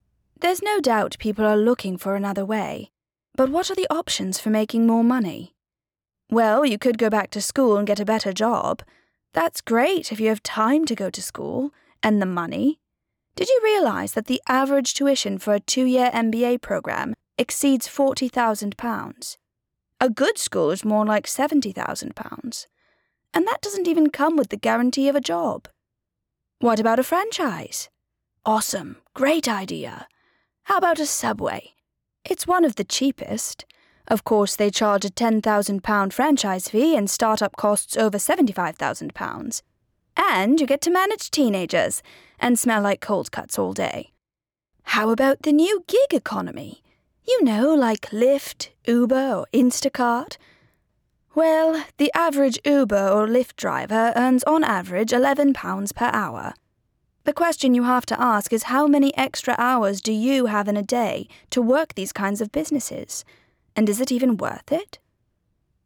Teenager, Adult, Young Adult
british english